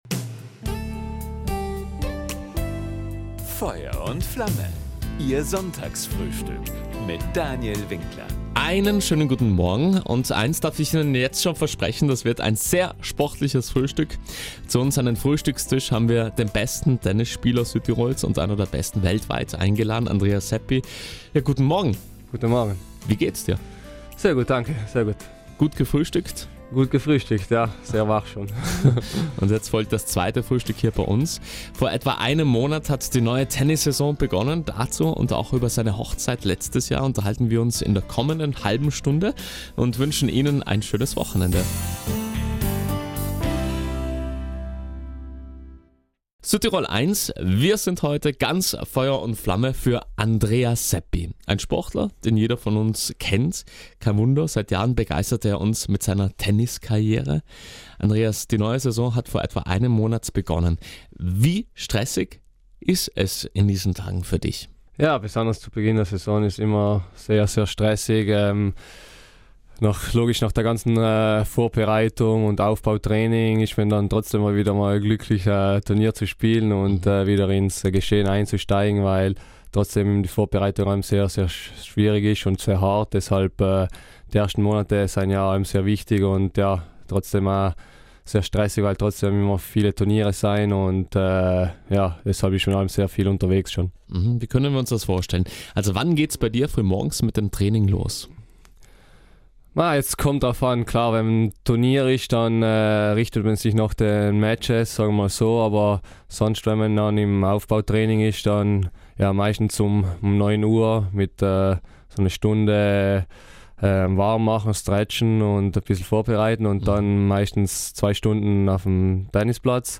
Beim Sonntagsfrühstück auf Südtirol 1 erzählte der Tennisprofi nicht nur von der Traumhochzeit mit seiner Traumfrau, sondern auch von seiner zweiten großen Liebe – dem Tennisspiel.